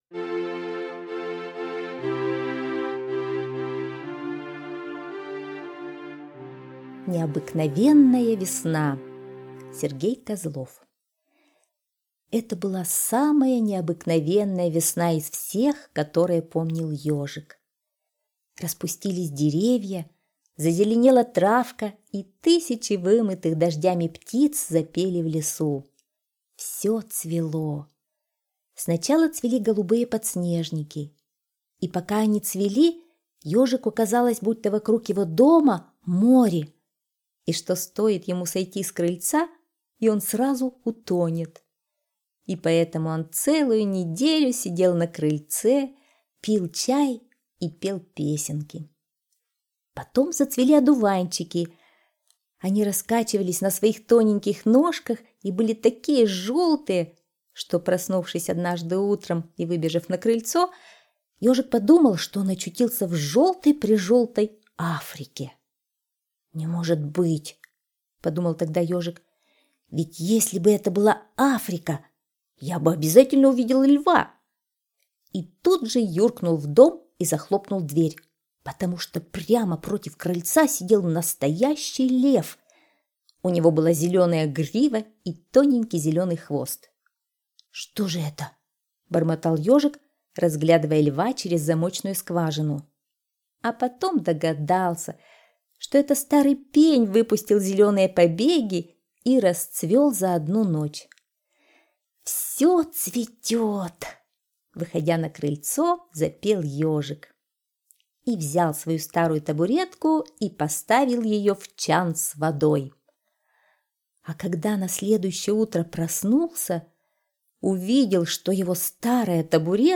Слушайте Необыкновенная весна - аудиосказка Козлова С.Г. Сказка про то, какая чудесная весна наступила, все вокруг расцветало и Ежик любовался этим.